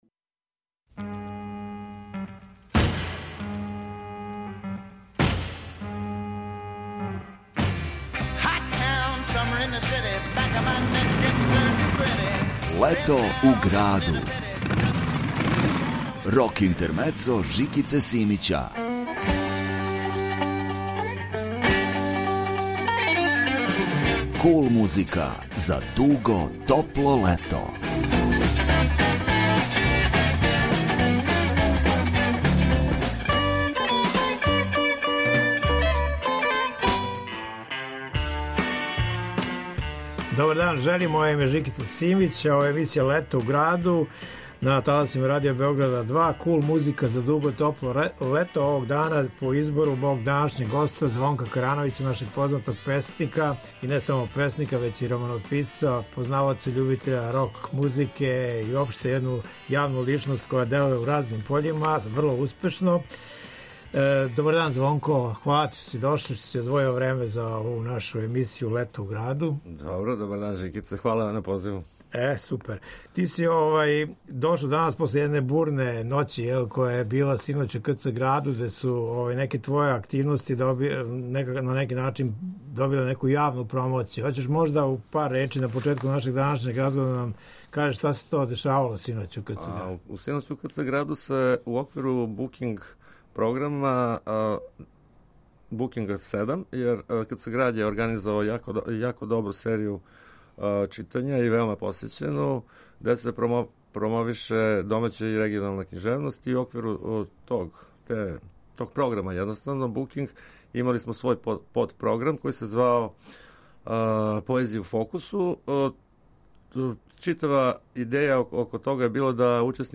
Kул музика за дуго топло лето.